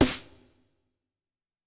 SouthSide Snare Roll Pattern (21).wav